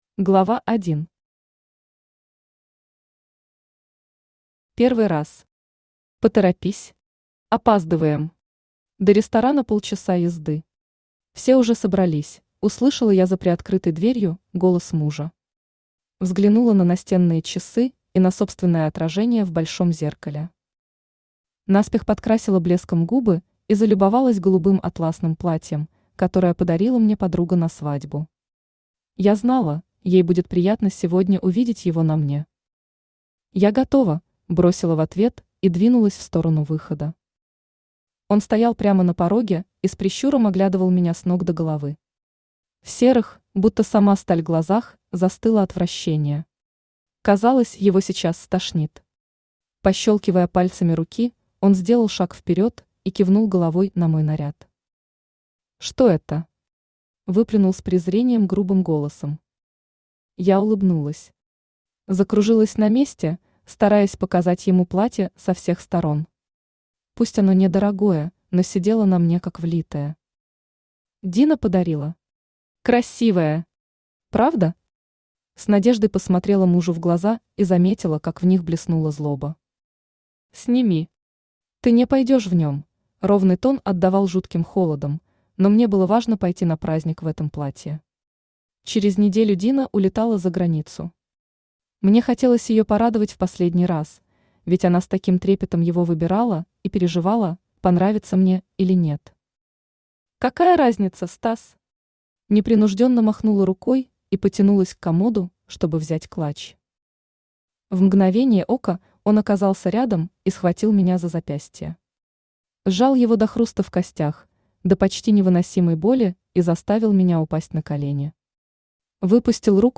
Aудиокнига В постели с волками Автор Юлия Пульс Читает аудиокнигу Авточтец ЛитРес.